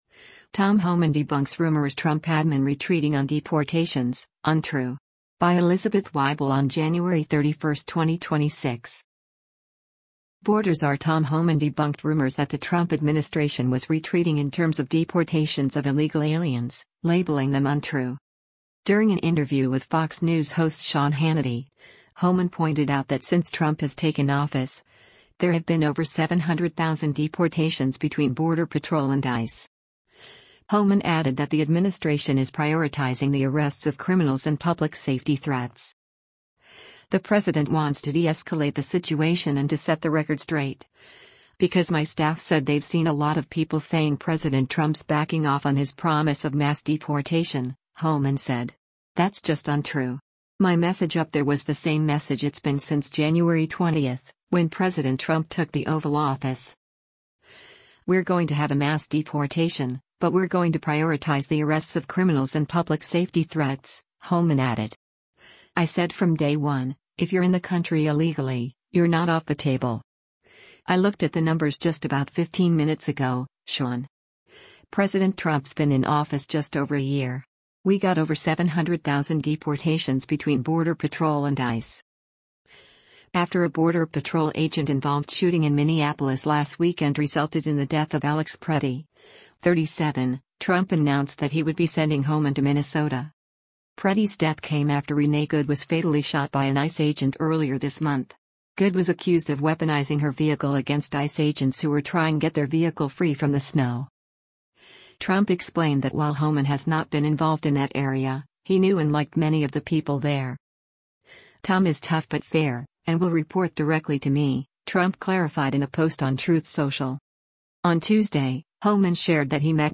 During an interview with Fox News host Sean Hannity, Homan pointed out that since Trump has taken office, there have been “over 700,000 deportations between Border Patrol and ICE.”